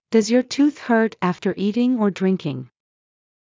ﾀﾞｽﾞ ﾕｱ ﾄｩｰｽ ﾊｰﾄ ｱﾌﾀｰ ｲｰﾃｨﾝｸﾞ ｵｱ ﾄﾞﾘﾝｷﾝｸﾞ